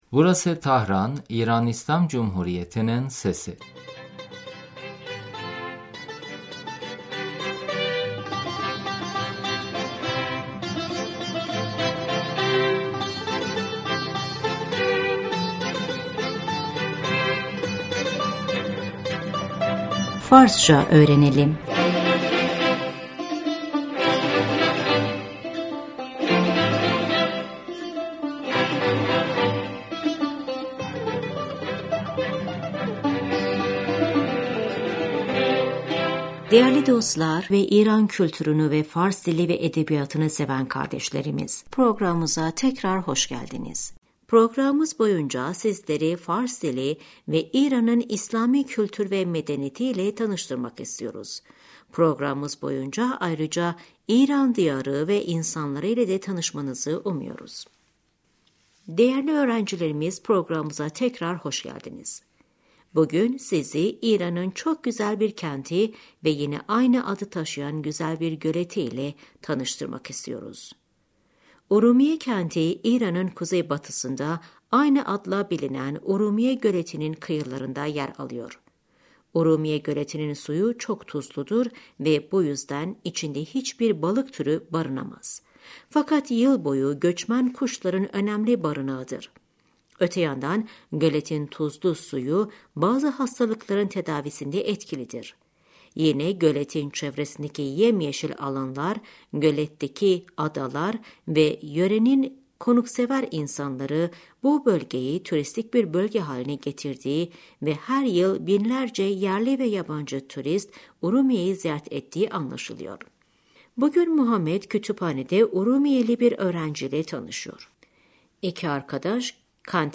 Şimdi bugünkü dersimizin ilk diyaloğunu dinleyelim.